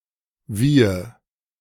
Wiehe (German pronunciation: [ˈviːə]